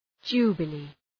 Προφορά
{‘dʒu:bə,li:}